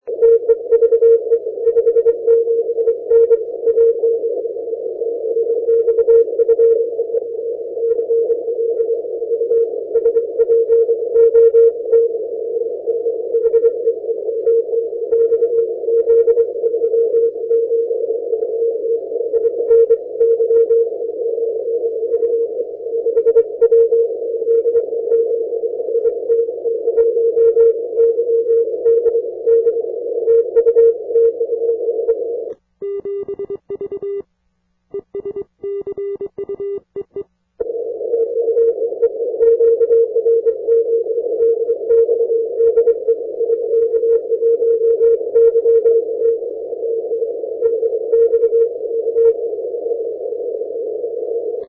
If possible use headphones to improve the copy....these recordings are mostly of difficult, lowband QSO's which are often just at the edge of readability.....some signals are very light, but  mostly all Q-5.....afterall...nobody works 80 or 160 without headphones...do they?